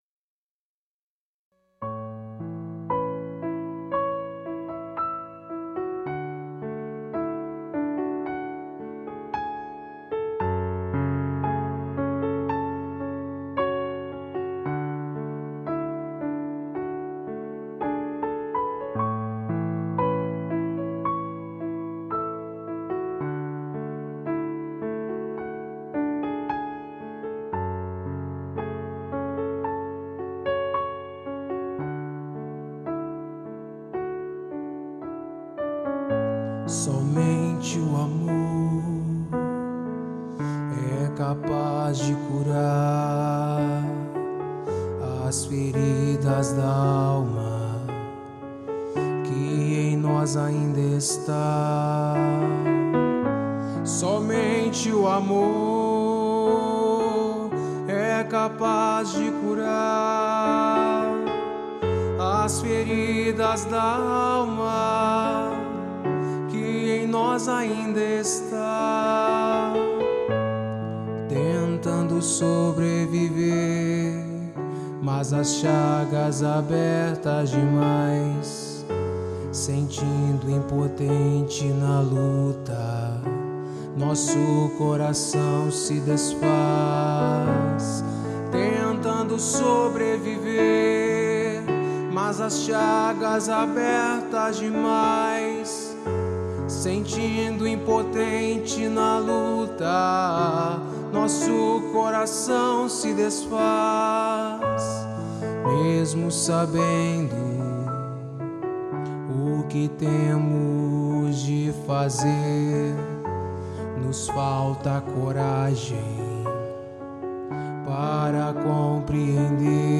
EstiloFolk